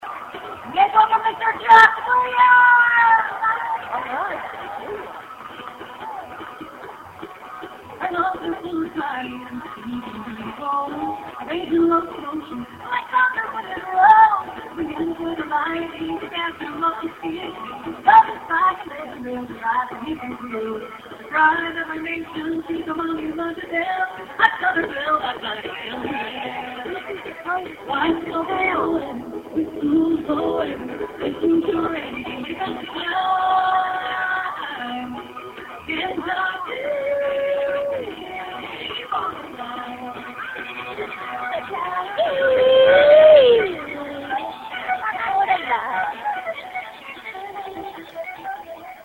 Recorded from my cell phone